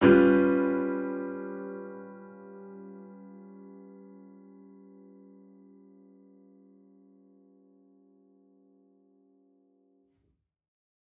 Index of /musicradar/gangster-sting-samples/Chord Hits/Piano
GS_PiChrd-G7b5.wav